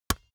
playerGroundHit1.wav